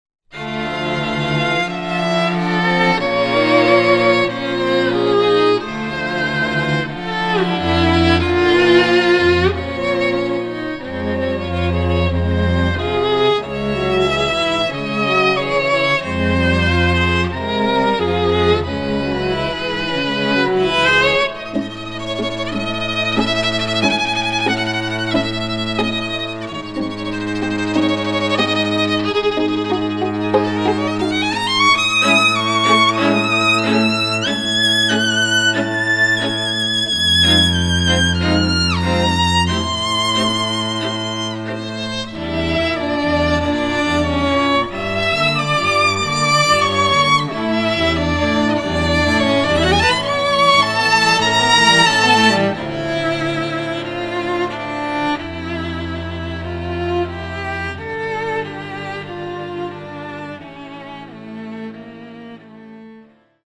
Recorded in June 2019 in Los Angeles